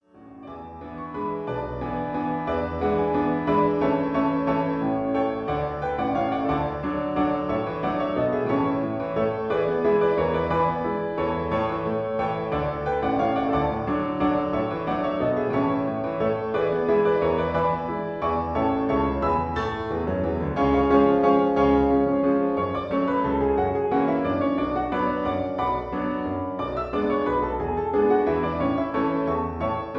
concert waltz